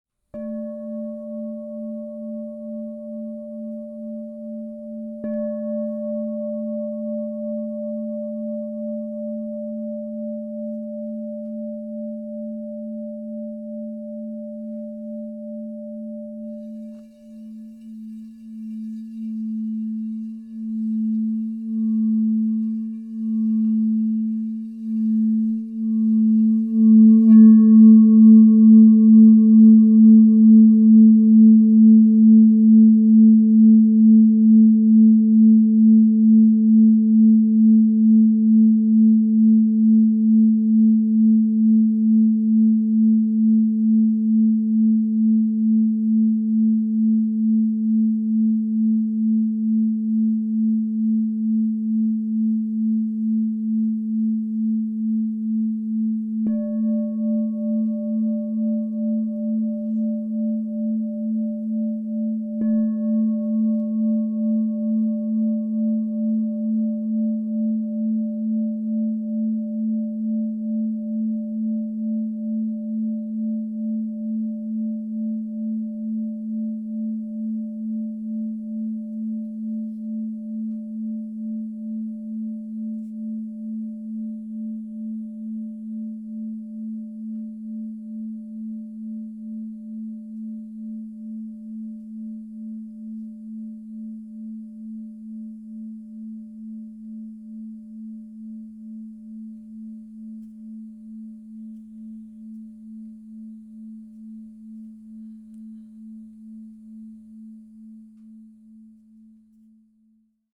Crystal Tones® Lemurian Seed 9 Inch A True Tone Singing Bowl
Tap into the ancient wisdom of the cosmos with the Crystal Tones® Lemurian Seed 9 inch A True Tone Singing Bowl, resonating at A +5 to inspire clarity, transformation, and spiritual elevation.
The expansive 9-inch size delivers rich, resonant tones, making it ideal for meditation, sound therapy, and enhancing sacred spaces.
Enhance your journey with 9″ Crystal Tones® True Tone alchemy singing bowl made with Lemurian Seed in the key of A +5.
+5 (True Tone)
440Hz (TrueTone), 528Hz (+)